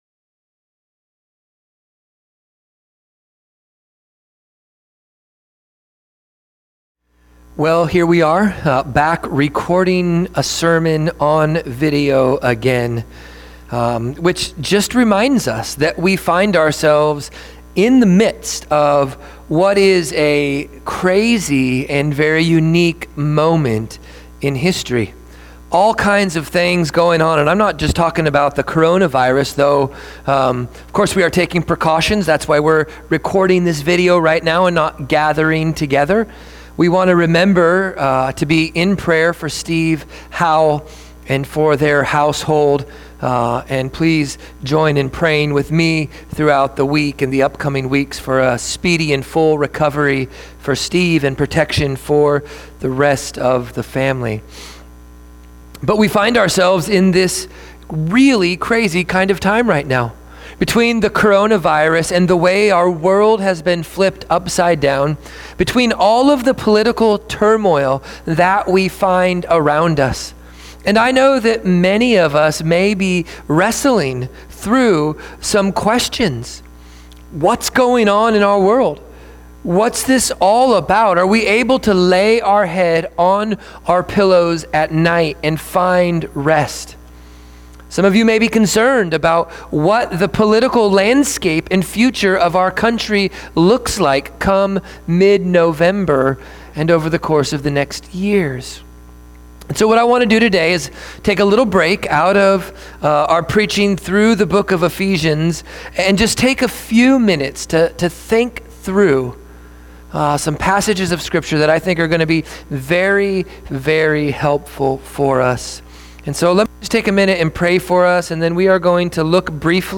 Oct 23, 2020 God is Sovereign, God is Good MP3 SUBSCRIBE on iTunes(Podcast) Notes Discussion Sermons in this Series In the midst of difficult times it's good to remember that God is both Sovereign over all things and good in and through all things.